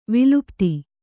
♪ vilupti